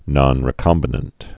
(nŏnrē-kŏmbə-nənt)